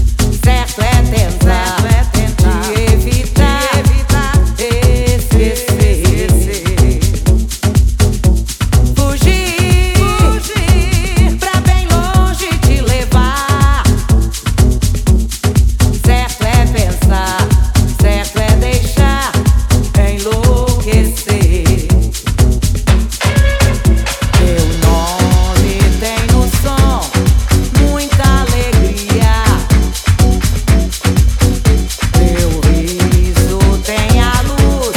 Жанр: Электроника